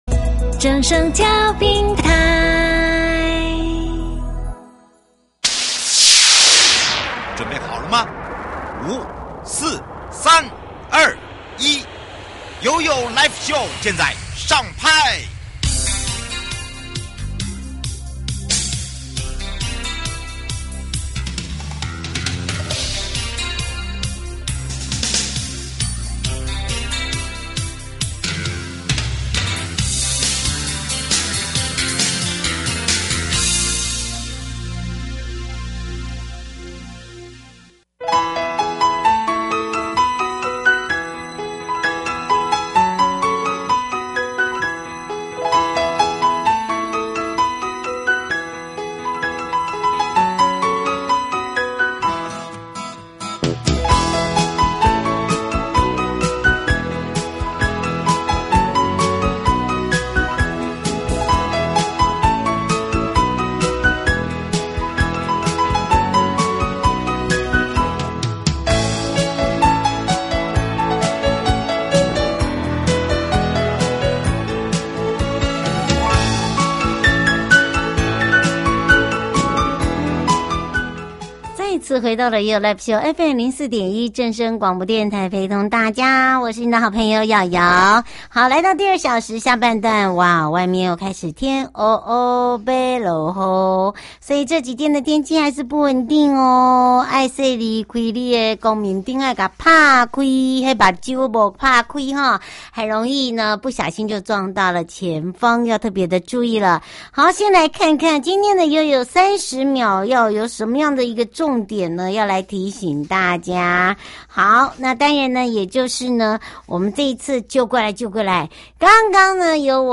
節目內容： 交通部航港局葉協隆局長（口播）